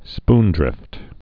(spndrĭft)